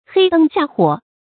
發音讀音
成語簡拼 hdxh 成語注音 ㄏㄟ ㄉㄥ ㄒㄧㄚˋ ㄏㄨㄛˇ 成語拼音 hēi dēng xià huǒ 發音讀音 感情色彩 中性成語 成語用法 作賓語、定語；形容黑暗沒有燈光的情景 成語結構 聯合式成語 產生年代 當代成語 近義詞 黑燈瞎火 反義詞 燈火輝煌 成語例子 下地做話，黑燈下火走回來，一進門，有飯吃，一拎壺，有水渴。